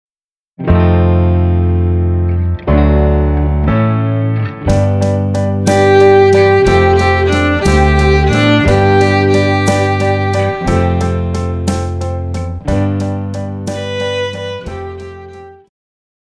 Pop
Viola
Band
Classics,POP,Ballad
Instrumental
Rock,Ballad
Only backing